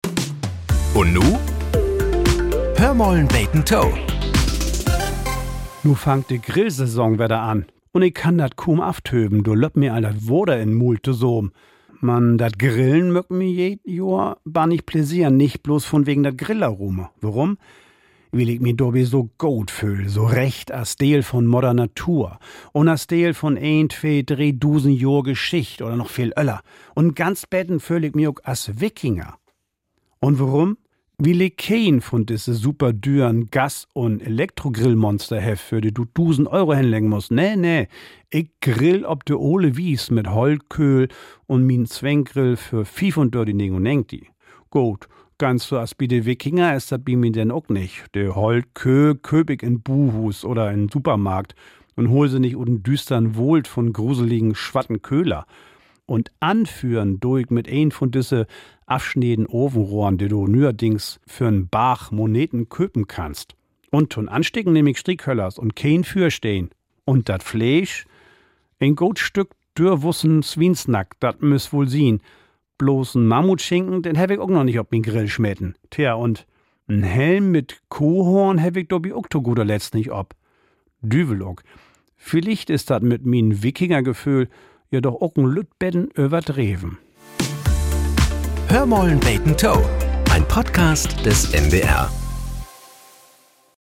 Nachrichten - 24.05.2025